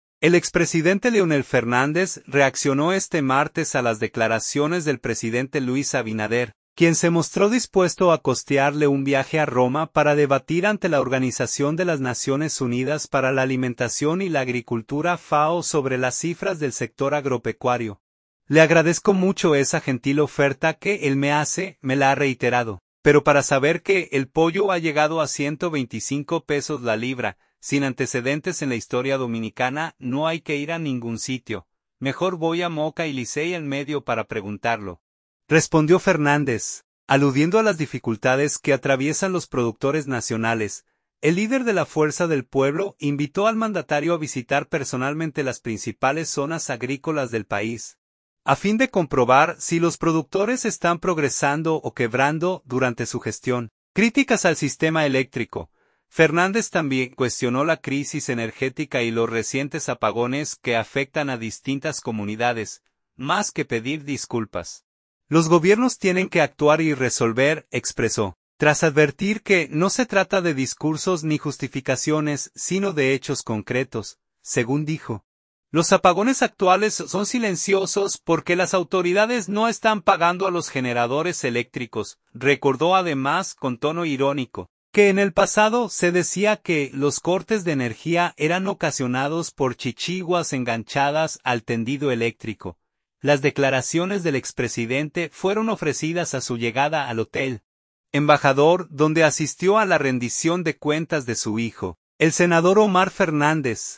Las declaraciones del expresidente fueron ofrecidas a su llegada al Hotel Embajador, donde asistió a la rendición de cuentas de su hijo, el senador Omar Fernández.